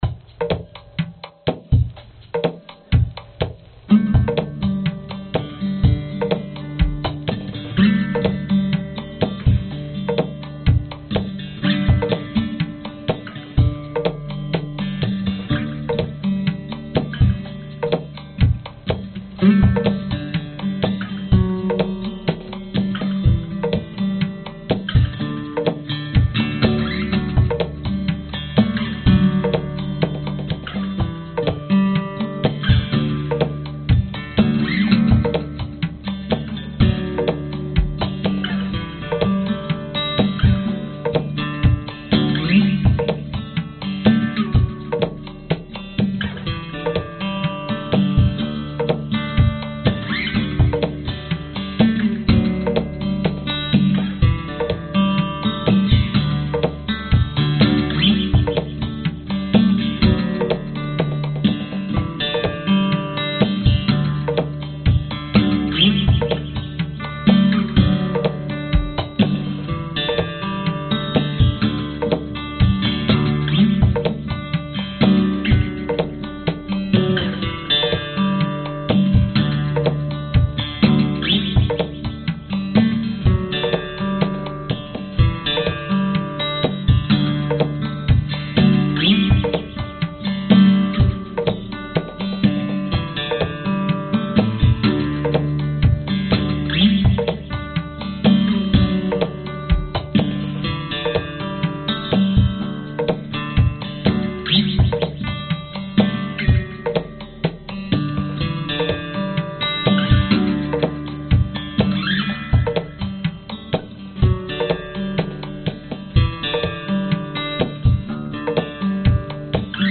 描述：现场原声吉他和VST打击乐器。将添加音轨。
Tag: 原声 吉他 打击乐 民间 圆润 电影音乐 器乐